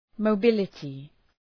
Shkrimi fonetik {məʋ’bılətı}